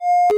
shutdown.ogg